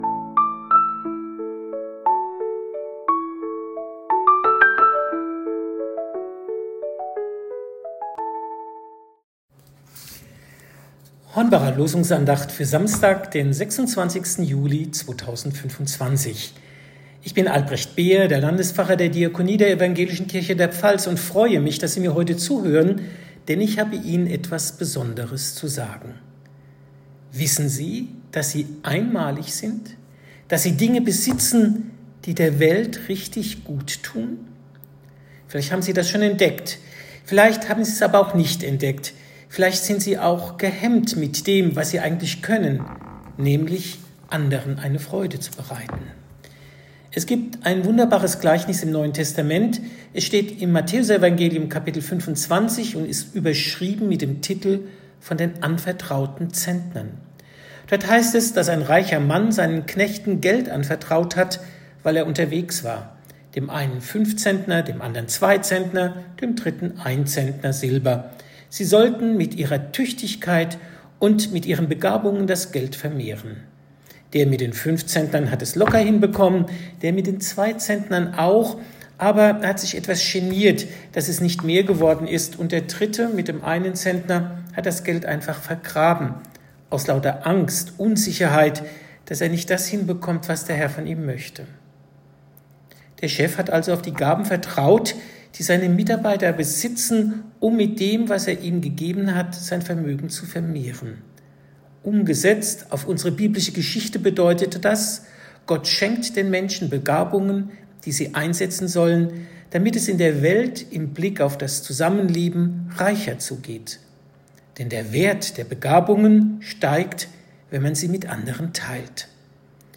Losungsandacht für Samstag, 26.07.2025